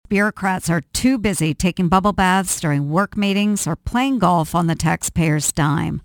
She commented briefly Wednesday during a conference call with Iowa reporters.